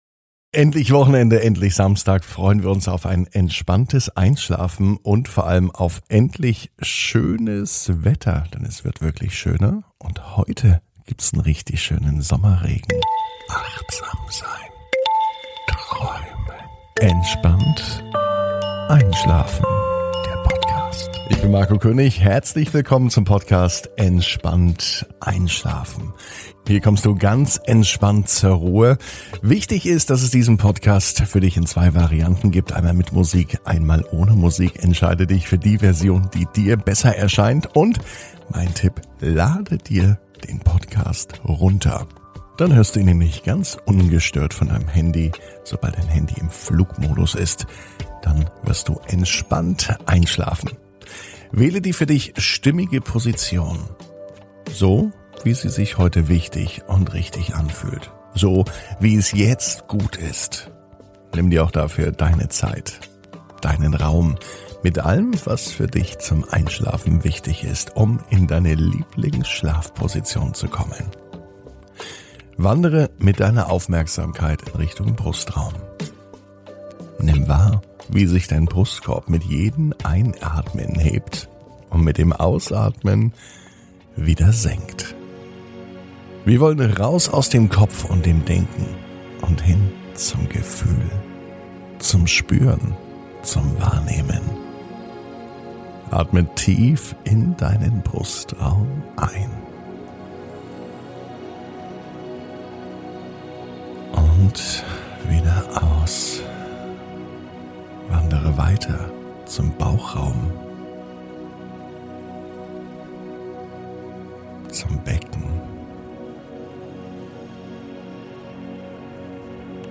Heute startet der neue Einschlafpodcast Entspannt einschlafen.